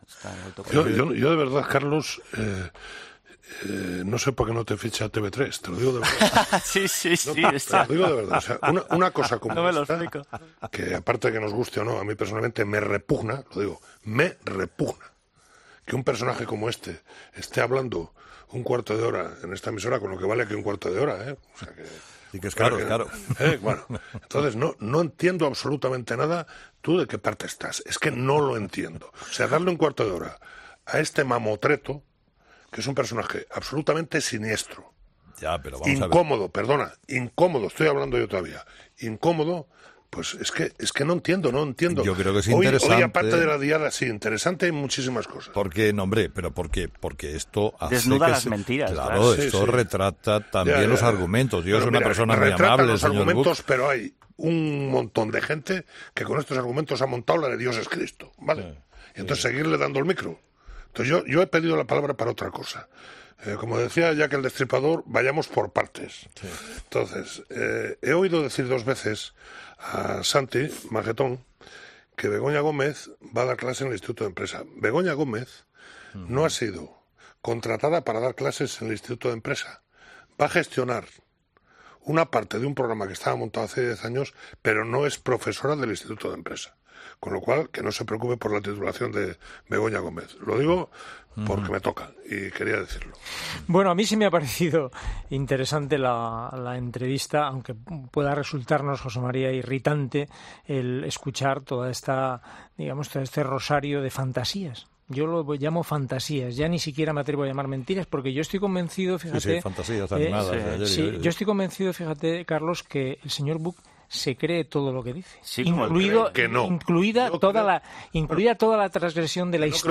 Entrevista a Miquel Buch
Entrevistado: "Miquel Buch i Moya"
Carlos Herrera ha entrevistado este martes a Miquel Buch, conseller de Interior de la Generalitat, en las horas previas a la celebración de la Diada.